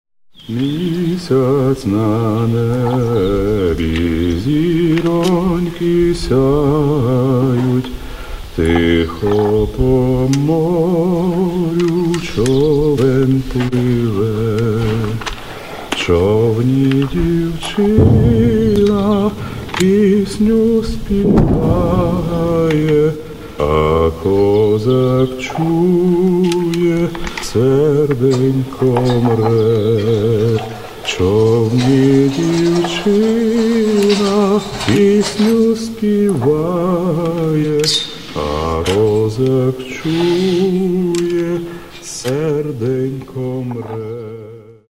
музыка: українська народна